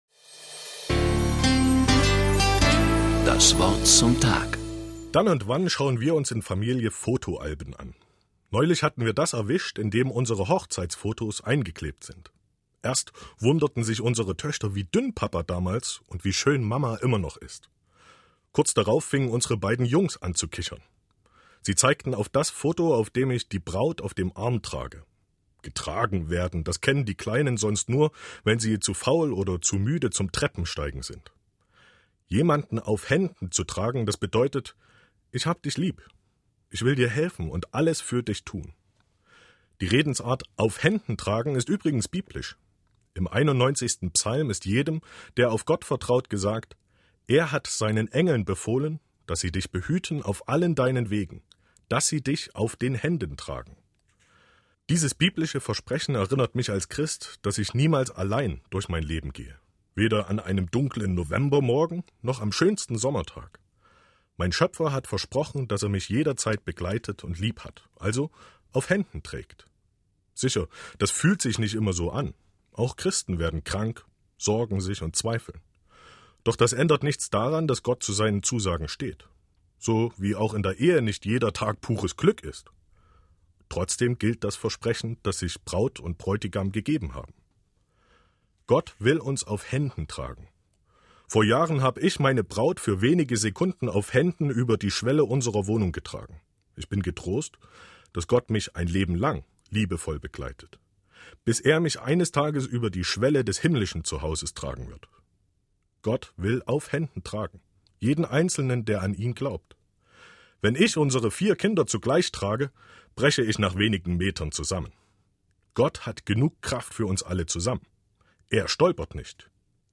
In der Woche vor dem Ewigkeitssonntag hatte unsere Kirche in Sachsen die Möglichkeit, das „Wort zum Tag“ im MDR zu gestalten.